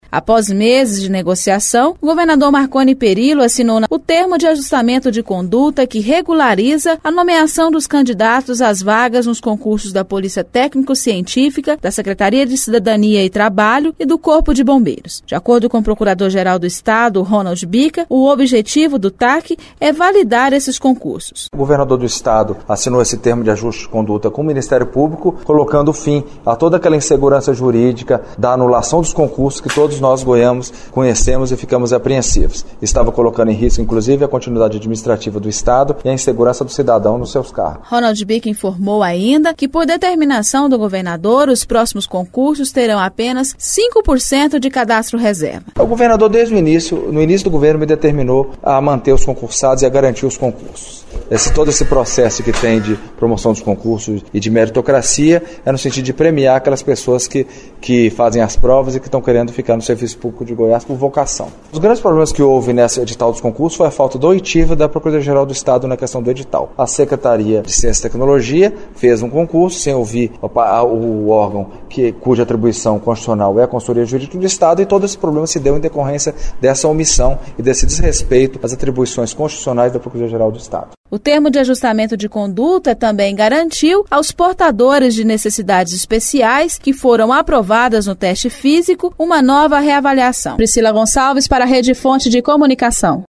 Com isso, os quase 4 mil candidatos já empossados nas Secretarias de Cidadania e Trabalho, Polícia Técnico-Científica e Corpo de Bombeiros serão mantidos definitivamente nos cargos. O assunto na reportagem